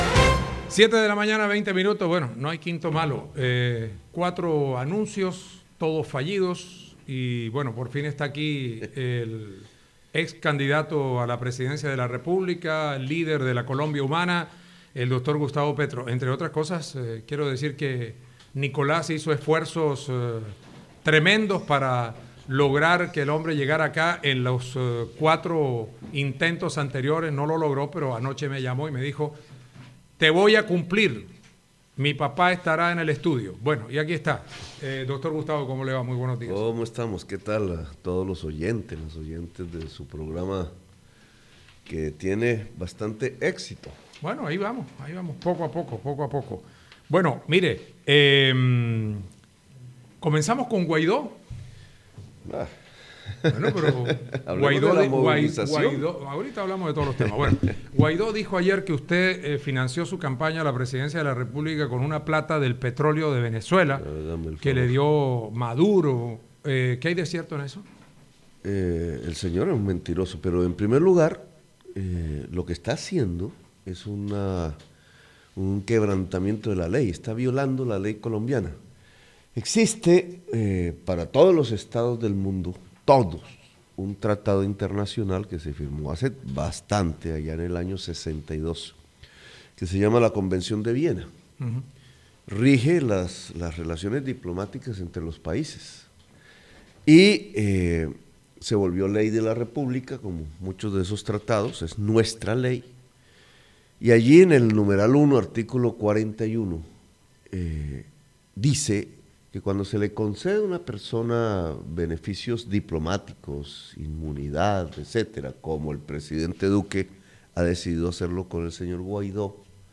En diálogo con Emisora Atlántico, el ex candidato presidencial Gustavo Petro calificó de mentiroso y mezquino a Juan Guaidó, al líder opositor venezolano, por afirmar que su campaña fue financiada con dineros del régimen del Presidente Nicolás Maduro.